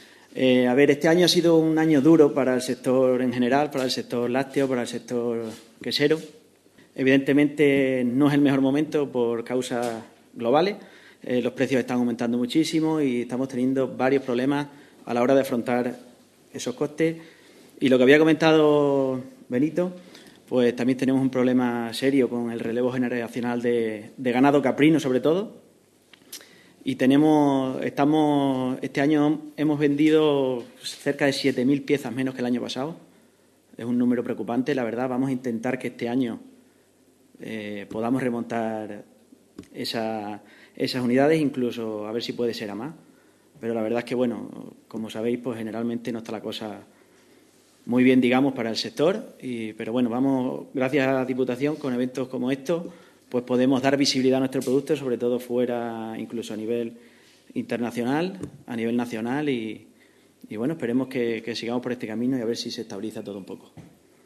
Y así lo ha expresado la Vicepresidenta 1ª de la Diputación de Cáceres, Esther Gutiérrez, en la rueda de prensa de presentación a medios de la programación de esta Feria que tendrá lugar los días 28 y 29 de marzo en esa localidad.